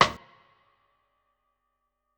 Kit A07.wav